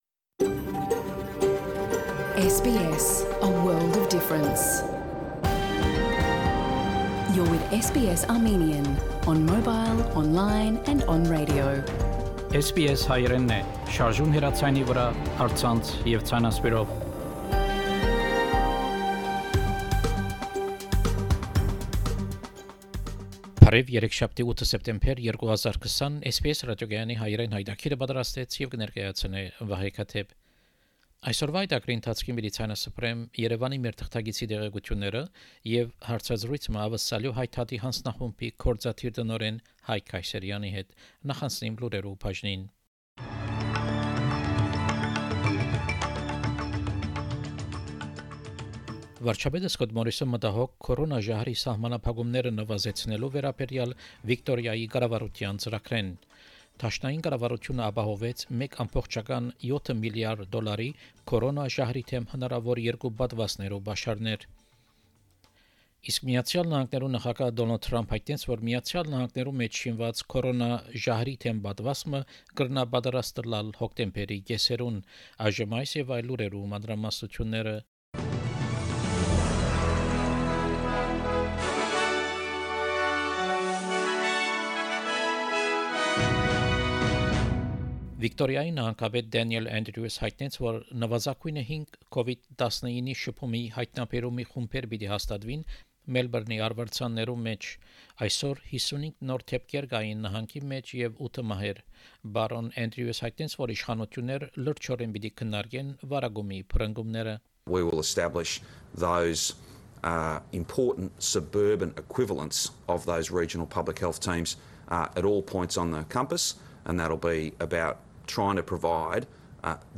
SBS Armenian news bulletin from September 8, 2020 program.